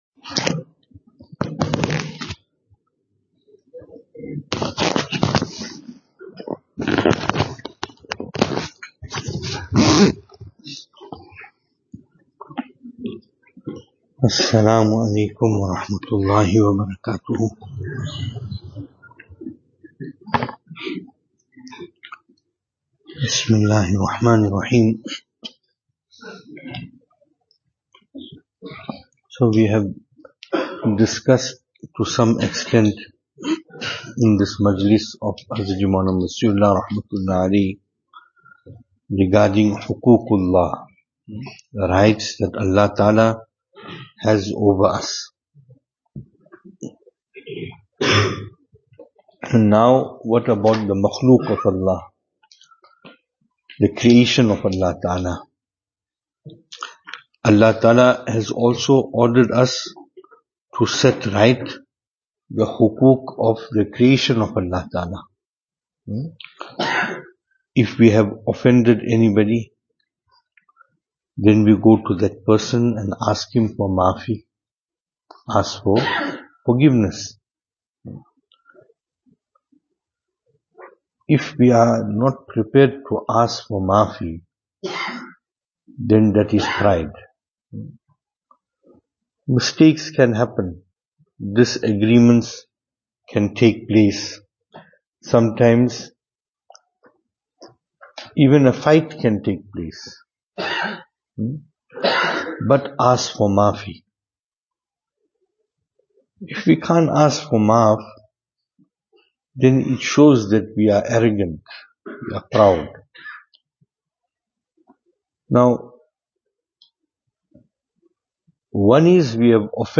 Venue: Albert Falls , Madressa Isha'atul Haq
Service Type: Islahi Majlis